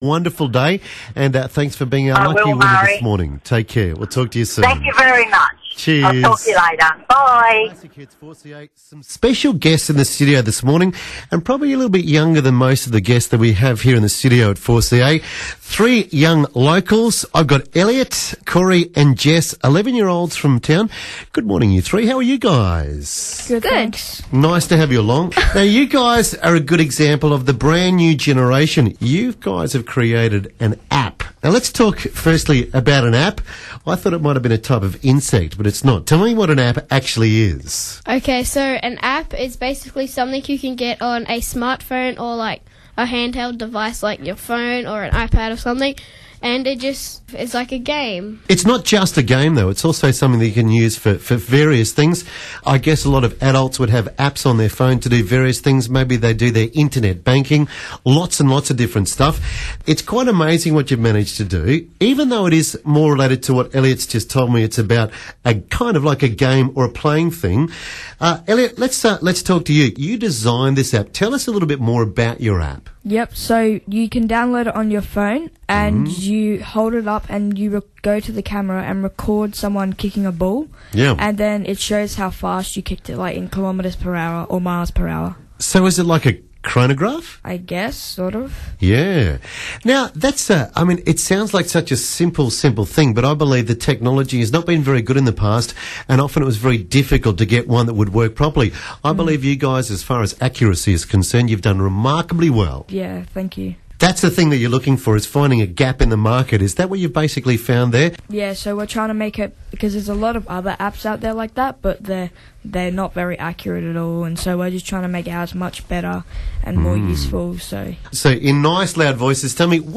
meets three of the young entrepreneurs from Cairns behind a new app that measures the speed of a kicked or thrown ball